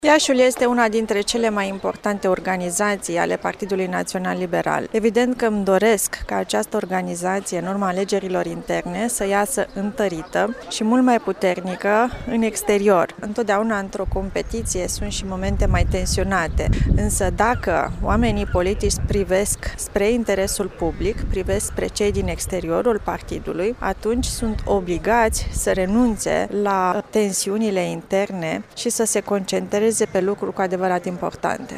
La Iaşi se desfăşoară conferinţa pentru alegerea noilor structuri de conducere ale organizaţiei judeţene a PNL.
Într-o declaraţie pentru postul nostru de radio, Raluca Turcan a precizat că alegerile de astăzi sunt foarte importante, deoarece, pentru viitoarele scrutinuri locale şi parlamentare din 2020, liberalii de la Iaşi trebuie să vină cu un mesaj foarte clar pentru un electorat preponderent de dreapta.